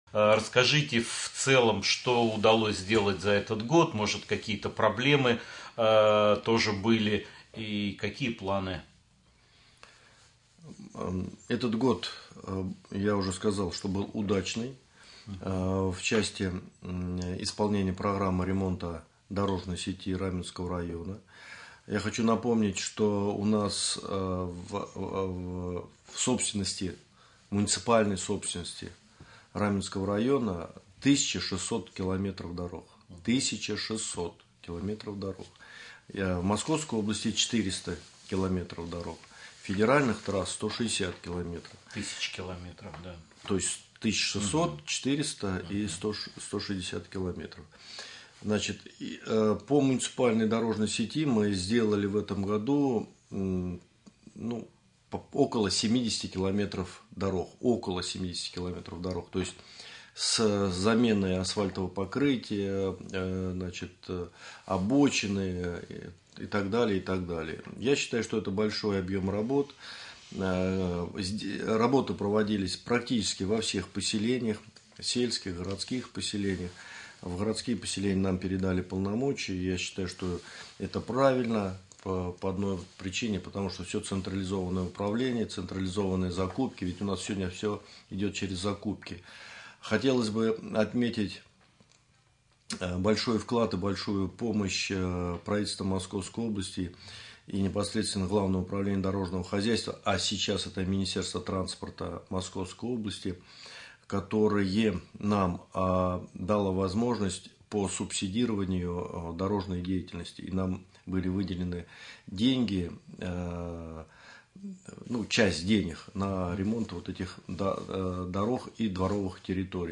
Около 70 км из них отремонтировали в 2017 году, об этом ходе прямого эфира на Раменском радио рассказал первый заместитель главы администрации Раменского района Николай Воробьев.
Как узнать, кому принадлежит дорога и к кому обратиться с вопросом по ее ремонту, слушайте в интервью ниже.